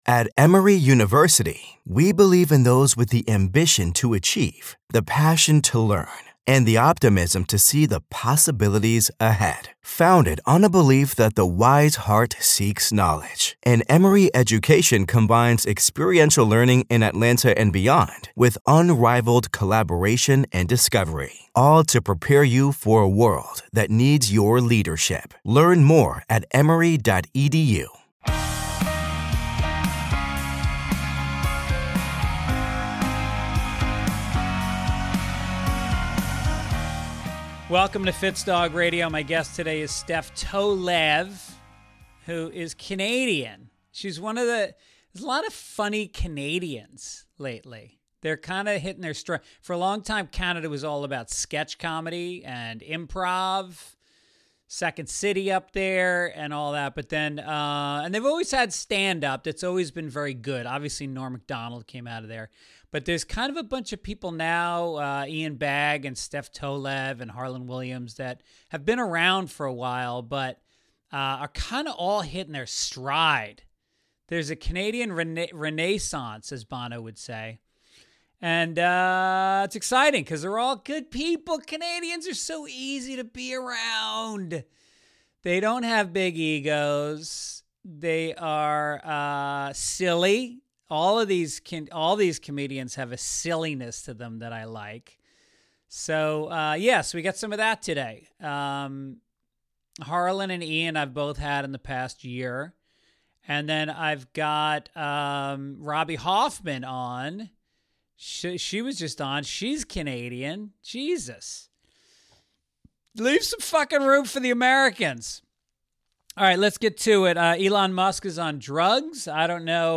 Honest funny interviews w/ Greg Fitzsimmons' new and old friends diving deep and laughing hard.